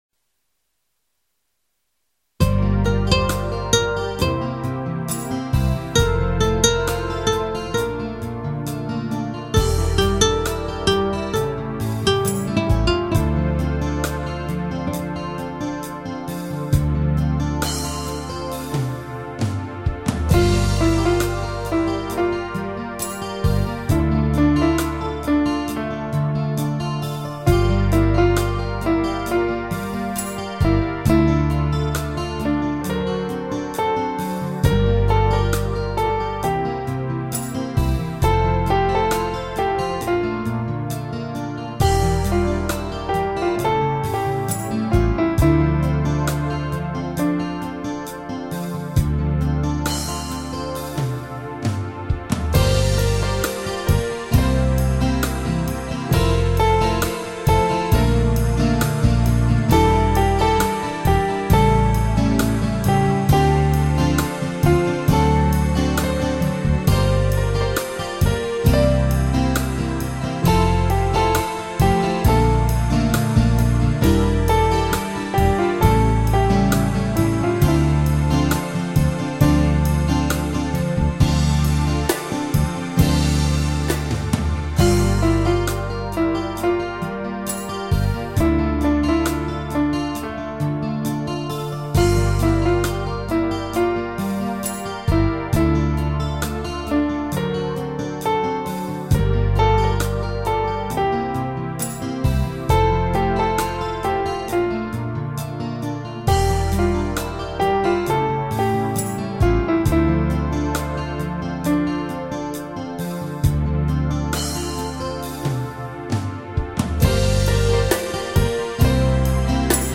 І слова, і музика гарні, спокійні. 39 39
Гарна почуттєва лірика, та й мелодія така зимова, спокійна 12 39 12